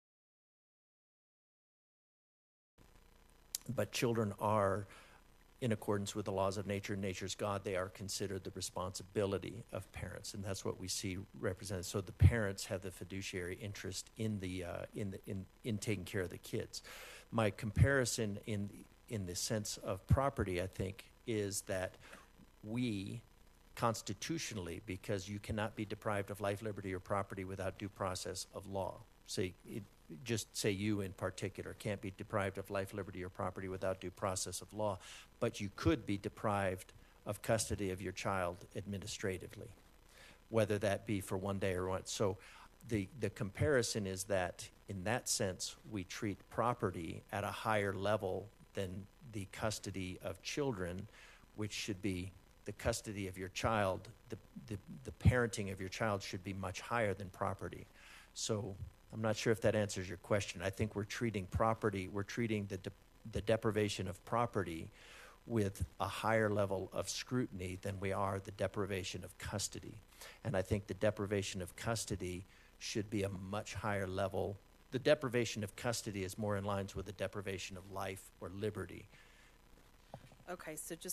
During his testimony, DeGraaf told the committee that he did not believe that children should be considered property…and then proceeded to explain why children should be considered property: